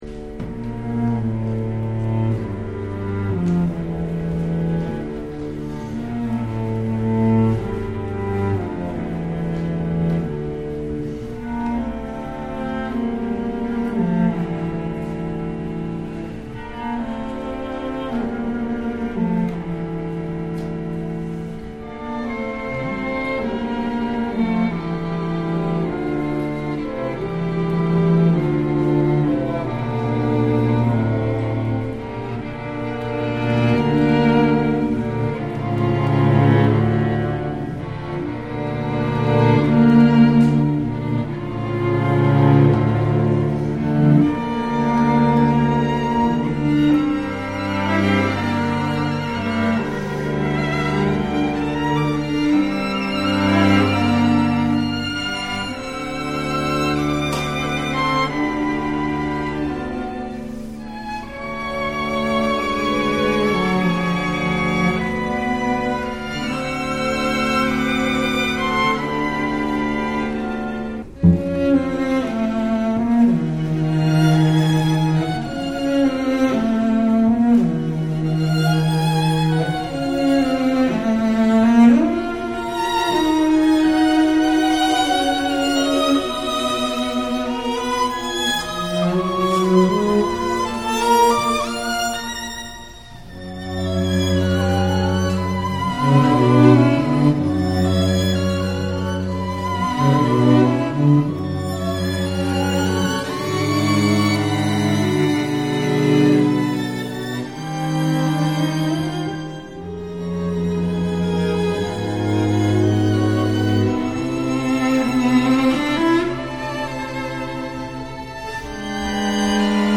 bg_music.2621b66e67b03f0b1bbc.mp3